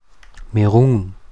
Meran_Mundart.mp3